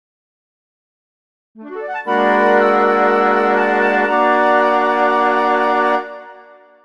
Musikalische Einleitung